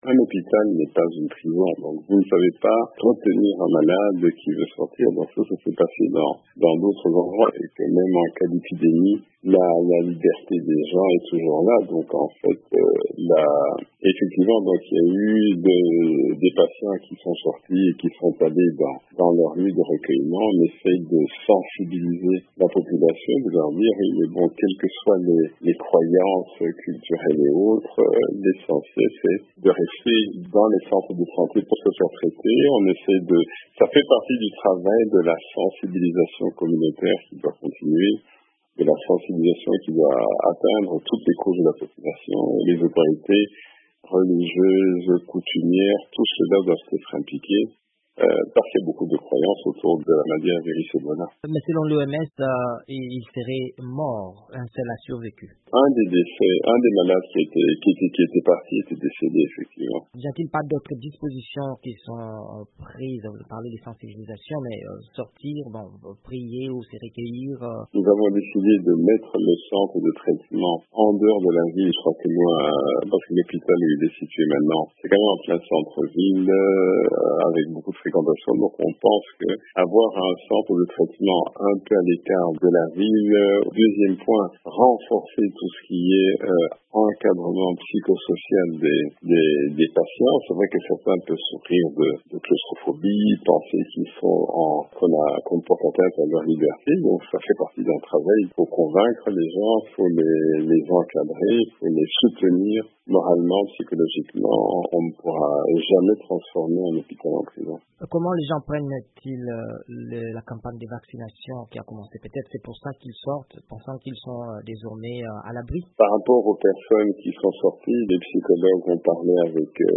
Le ministre congolais de la Santé, Oly Ilunga, a annoncé dans une interview à VOA Afrique que le centre de traitement des personnes souffrant de la maladie à virus Ebola à Mbandaka sera déplacé loin du centre-ville après l’évasion de malades.